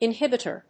音節in・híb・i・tor 発音記号・読み方
/‐ṭɚ(米国英語), ‐tə(英国英語)/